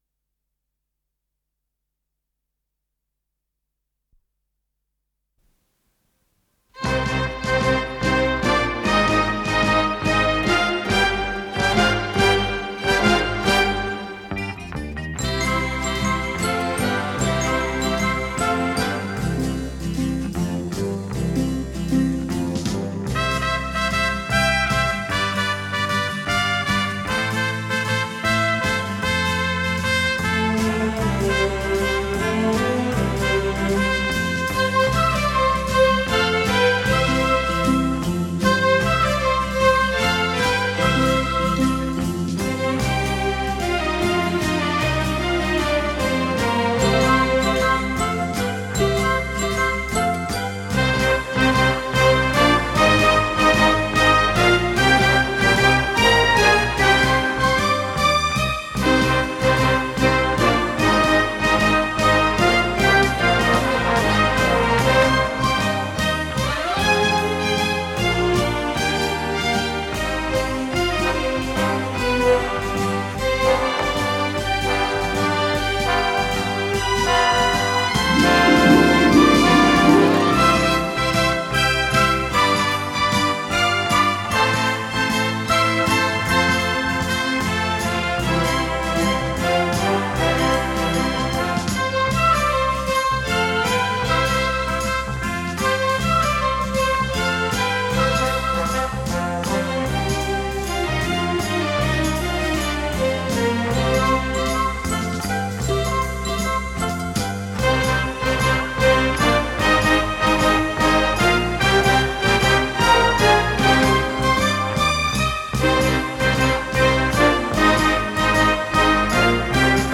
с профессиональной магнитной ленты
до мажор